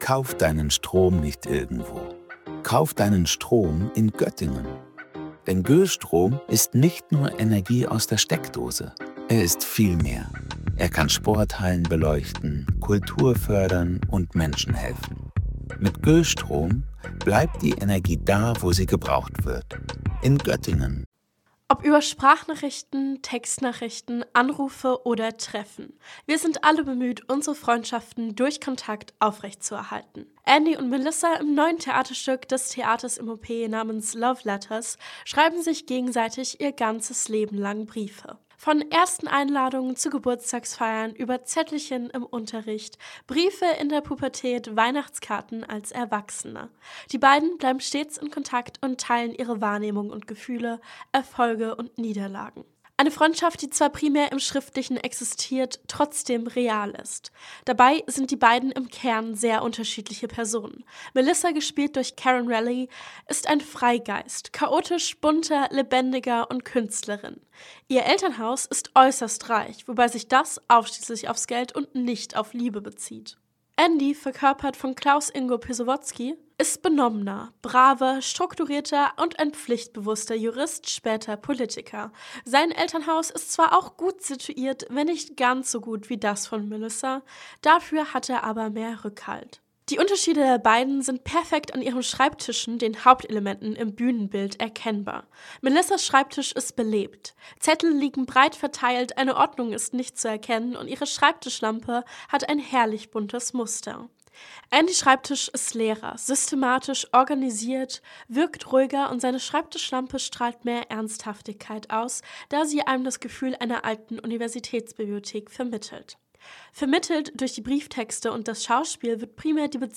Beiträge > Von Bekenntnissen und Missverständnissen – Rezension zu „Love Letters“ im ThOP - StadtRadio Göttingen